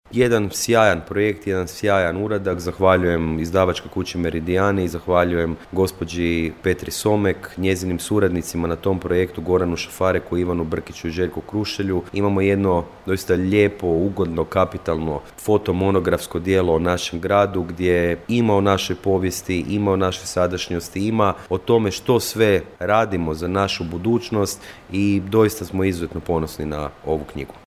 -rekao je gradonačelnik Grada Koprivnice i saborski zastupnik Mišel Jakšić.